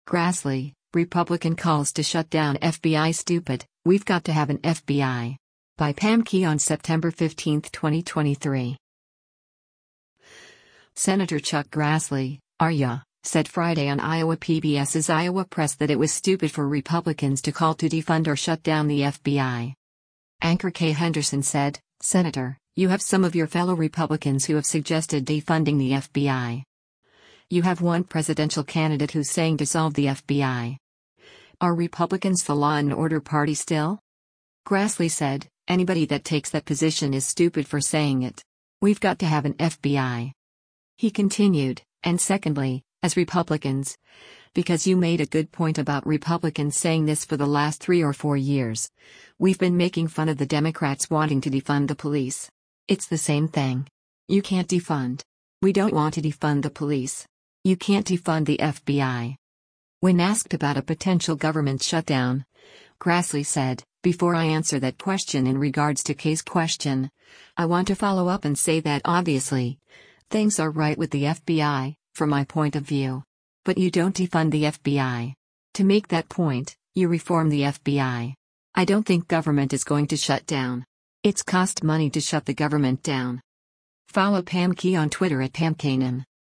Senator Chuck Grassley (R-IA) said Friday on Iowa PBS’s “Iowa Press” that it was “stupid” for Republicans to call to defund or shut down the FBI.